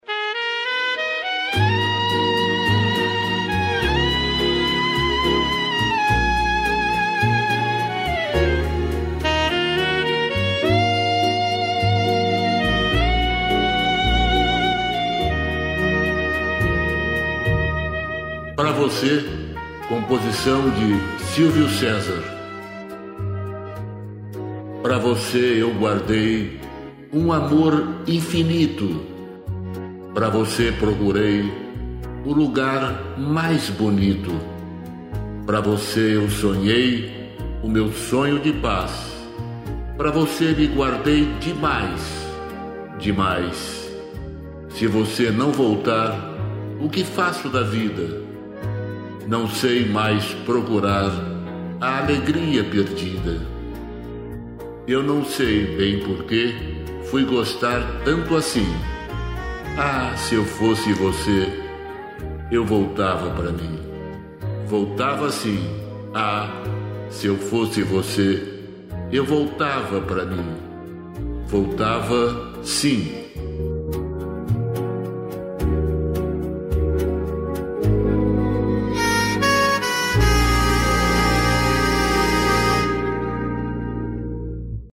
música, arranjo: IA